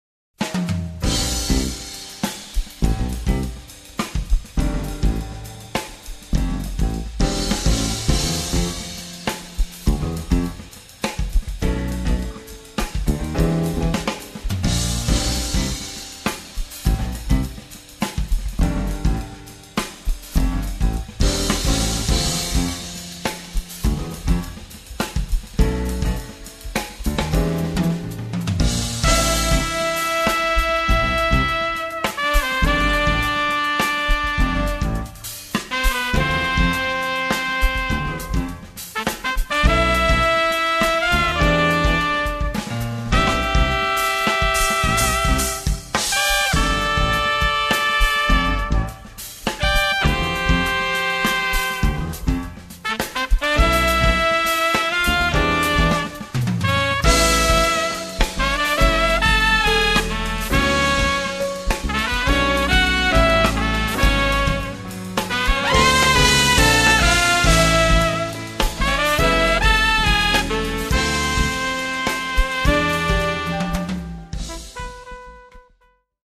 tromba e flicorno
sax tenore, alto e soprano
pianoforte
basso elettrico
batteria
secondo brano del disco è un funky in tre quarti